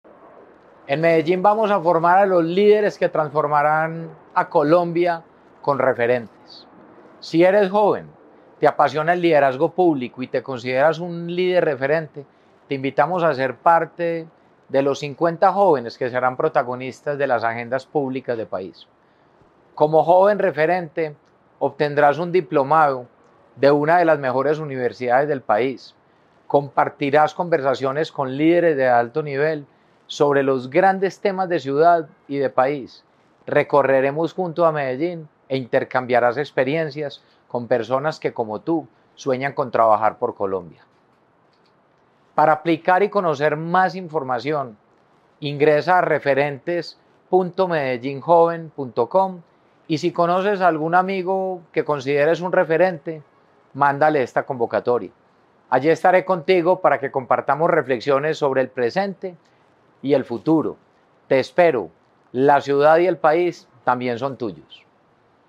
Palabras de Federico Gutiérrez Zuluaga, alcalde de Medellín Hasta el 20 de julio estará abierta la convocatoria de búsqueda de 50 líderes juveniles para transformar las agendas públicas del país.
Alcalde-Referentes-Juveniles-01.mp3